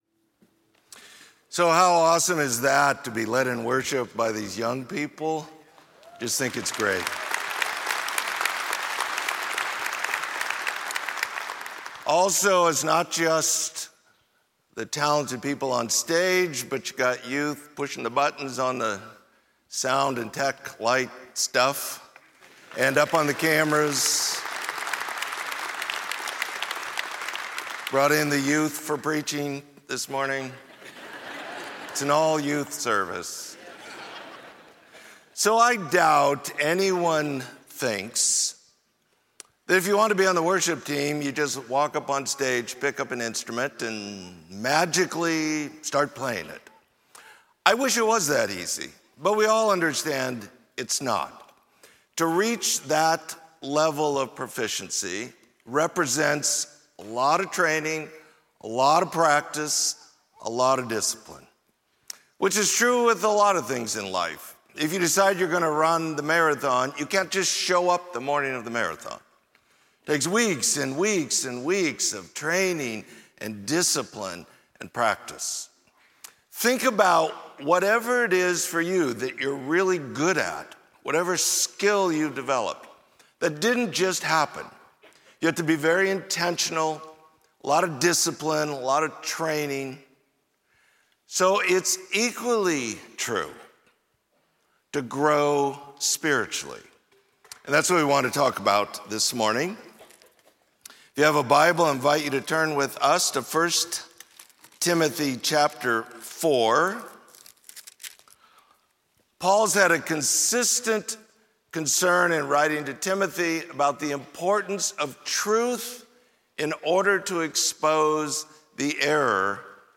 Sermon: Training in Godliness